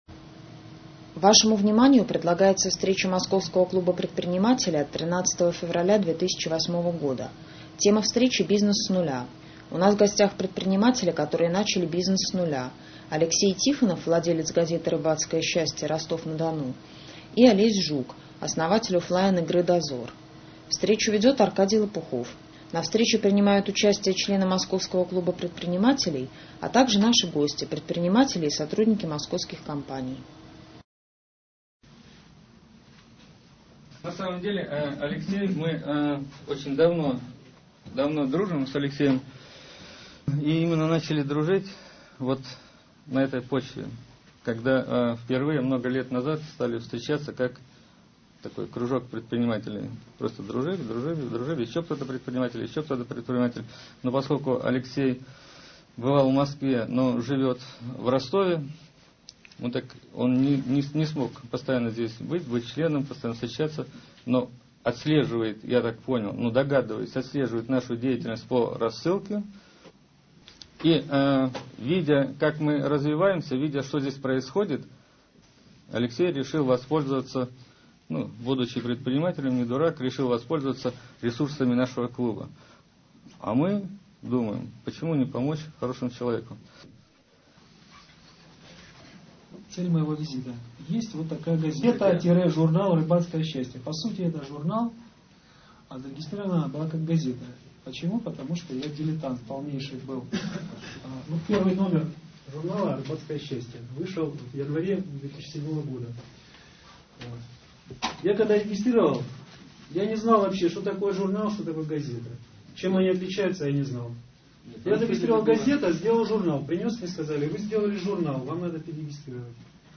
13.02.2008 состоялась ОТКРЫТАЯ встреча МКП.